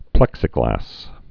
(plĕksĭ-glăs)